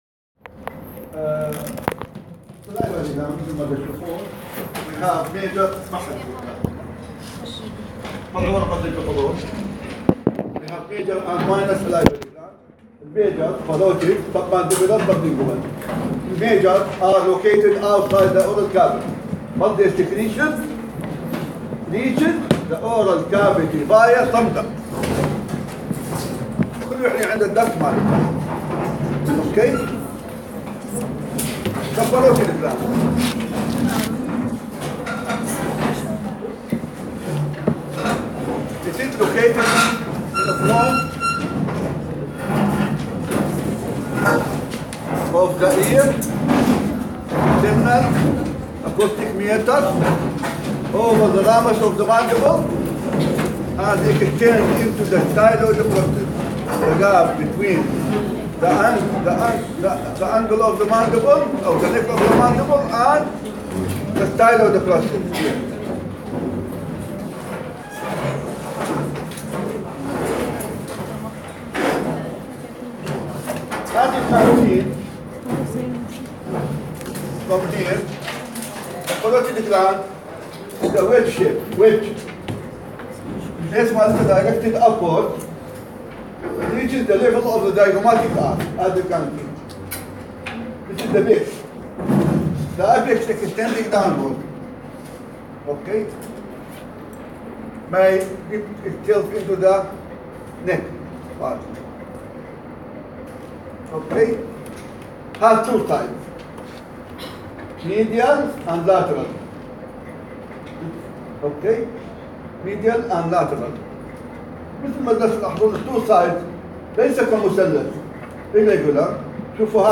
Head and Neck voice recording